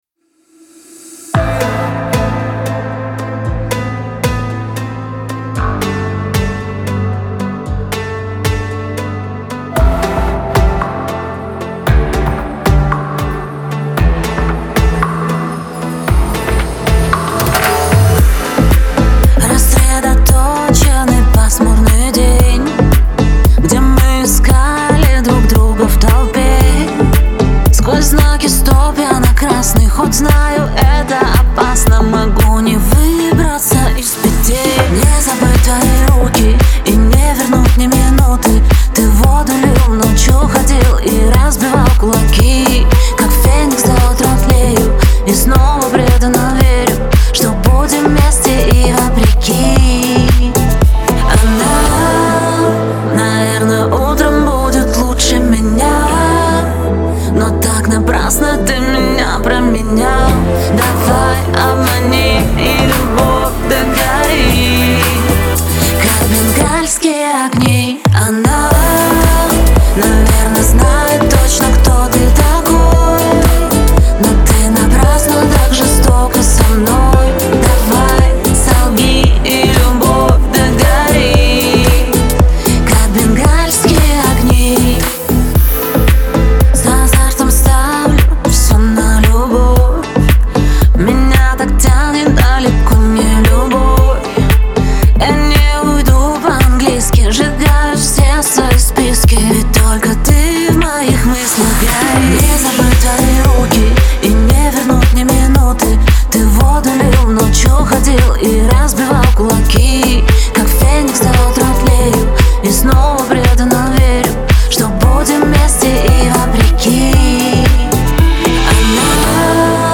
яркая и эмоциональная песня